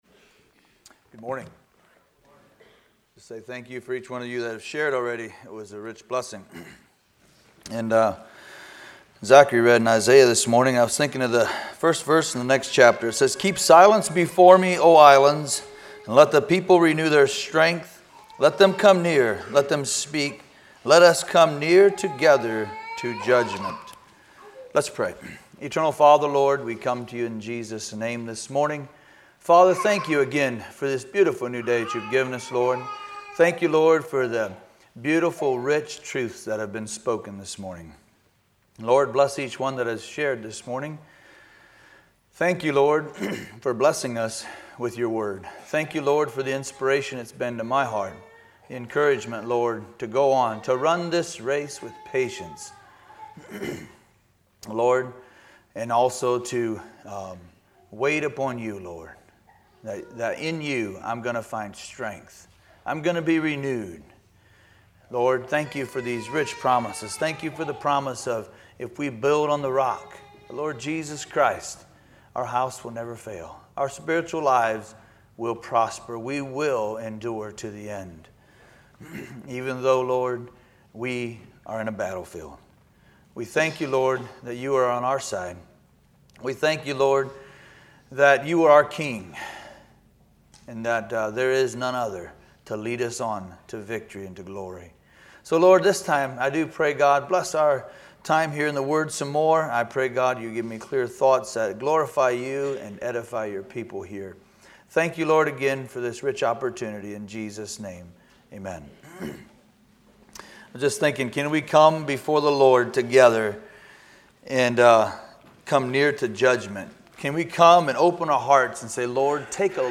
Sunday Morning Sermons